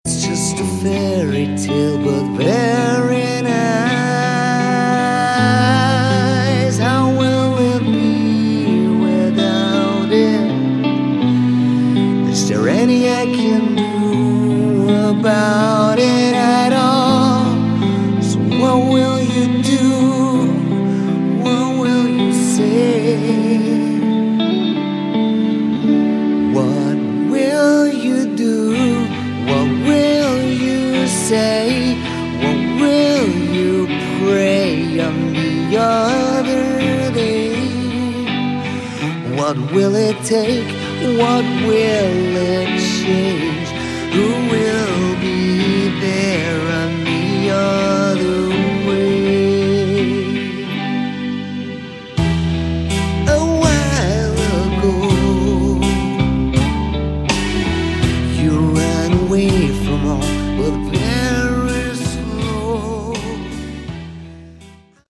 Category: Hard Rock
vocals
guitar
drums
bass